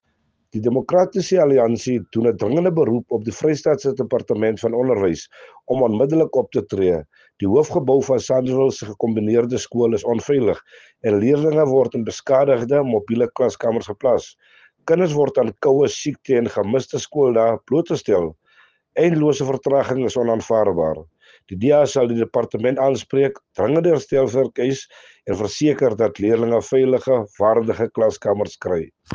Afrikaans soundbites by Cllr Robert Ferendale and